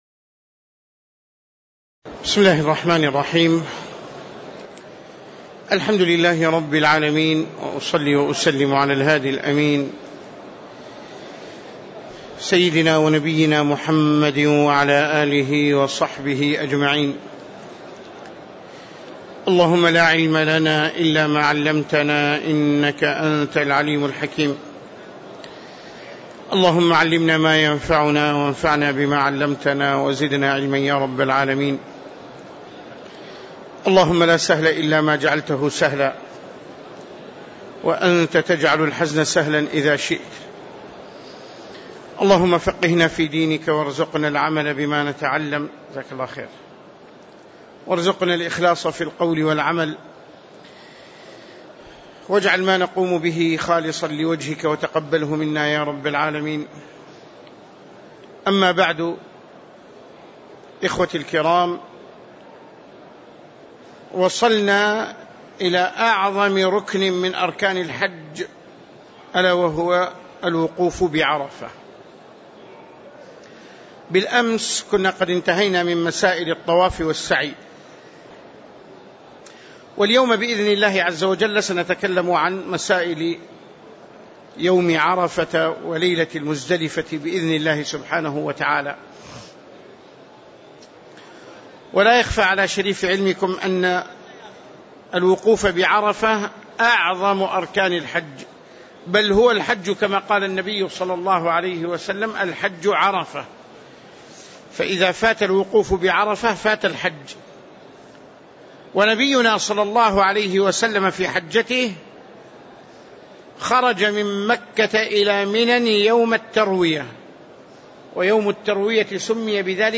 تاريخ النشر ٢٠ ذو القعدة ١٤٣٧ هـ المكان: المسجد النبوي الشيخ